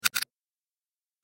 دانلود صدای ربات 31 از ساعد نیوز با لینک مستقیم و کیفیت بالا
جلوه های صوتی